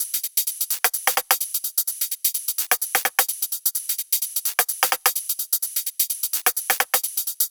VFH1 128BPM Southern Kit